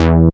cch_bass_one_shot_jack_E.wav